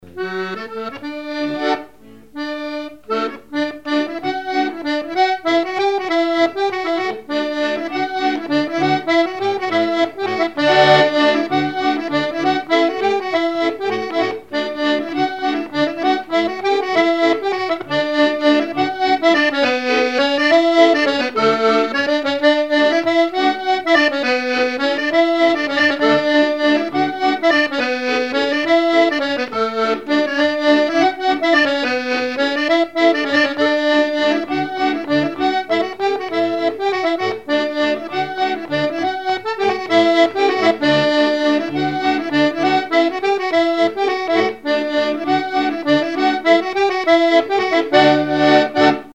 danse : mazurka
instrumentaux à l'accordéon diatonique
Pièce musicale inédite